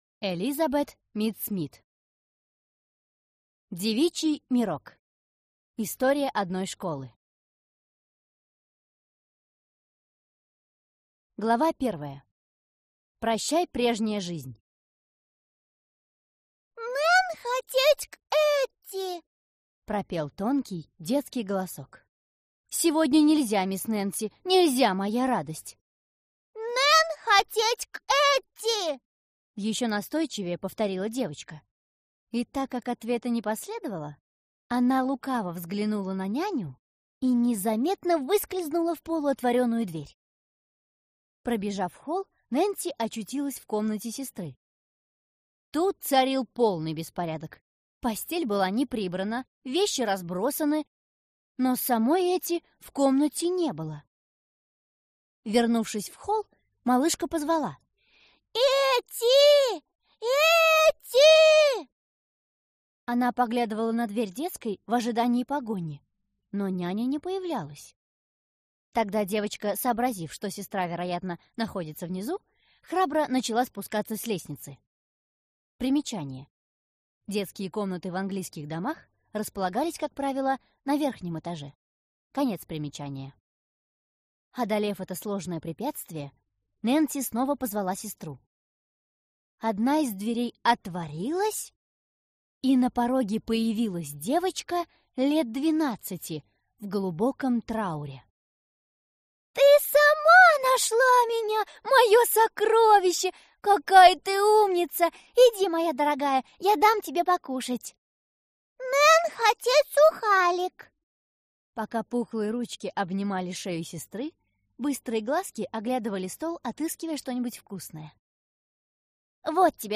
Aудиокнига Девичий мирок